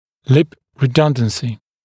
[lɪp rɪ’dʌndənsɪ][лип ри’дандэнси]избыточное смыкание губ, избыточный размер губ